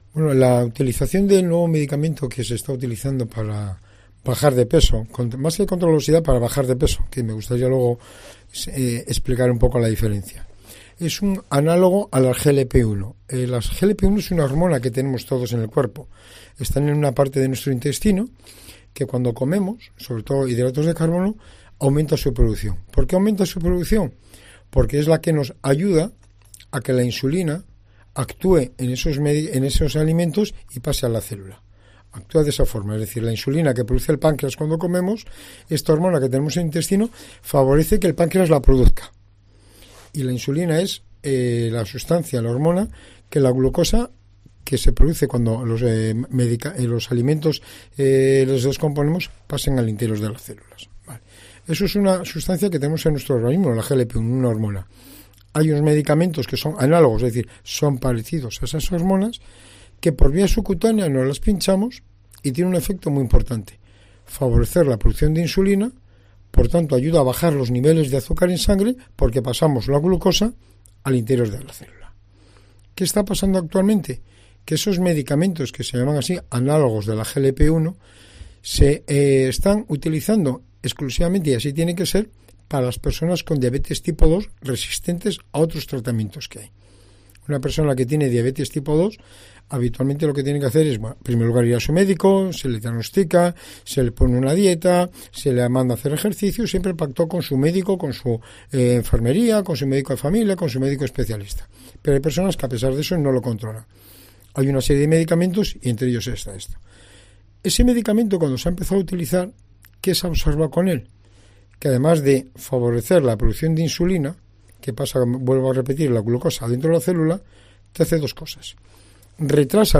Entrevista médico inyección para adelgazar